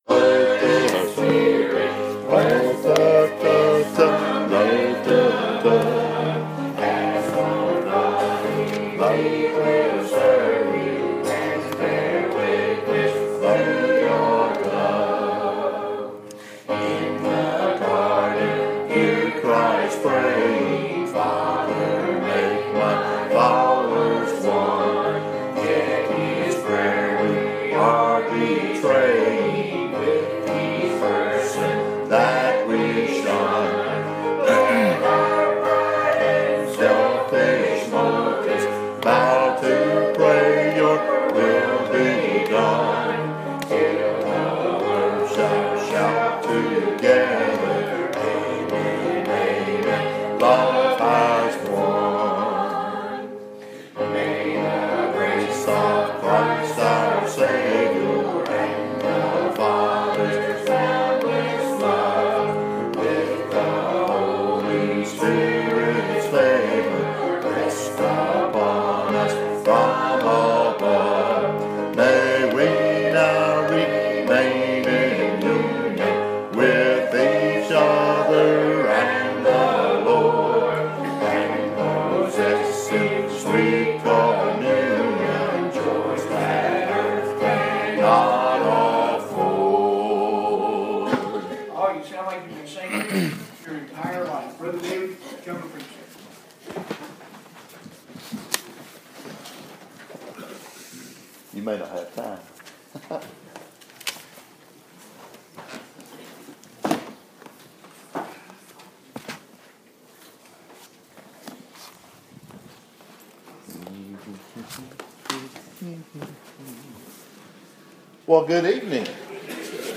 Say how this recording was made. Preached at Riverview Baptist (798 Santa Fe Pike Columbia Tn) the evening of May 28, 2017